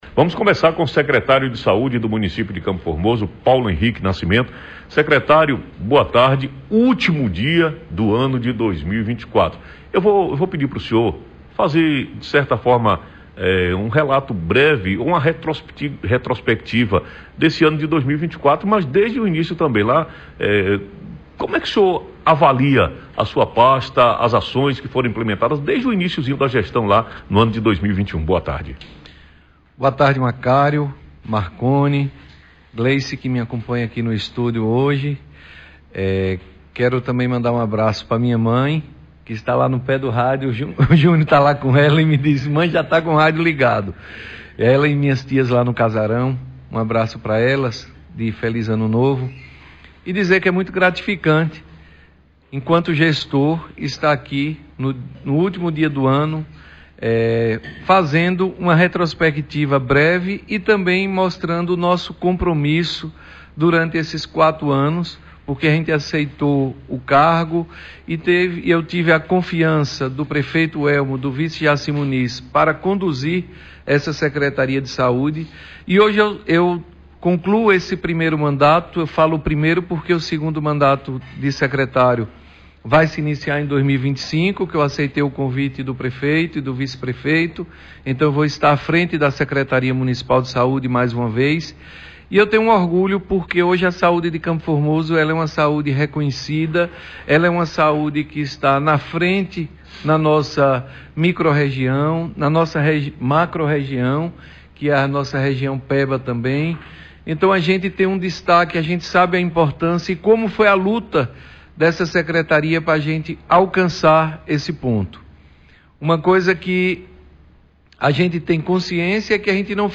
Entrevista ao vivo com o secretário de saúde, Paulo Henrique Nascimento